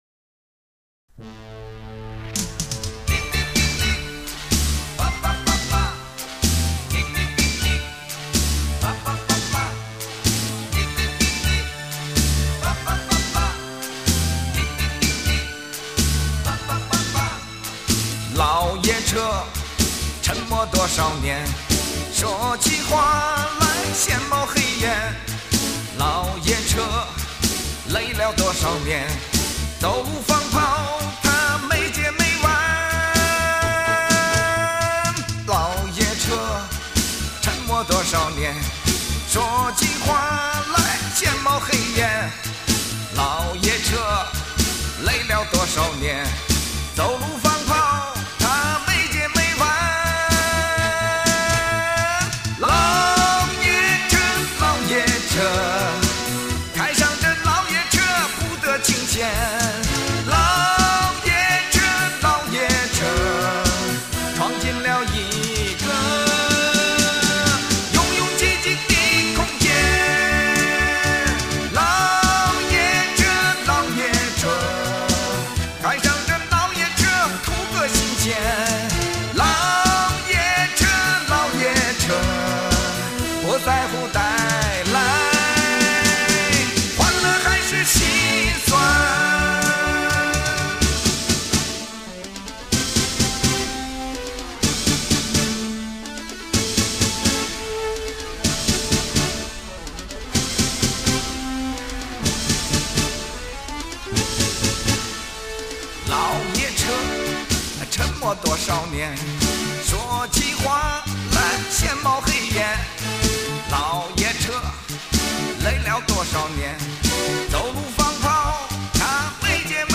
超长极限版本，弥足珍贵，原声电影歌曲大碟，顶尖音频技术处理中国电影博物馆馆藏珍品，史料详实，图文丰富，权威，鲜为人知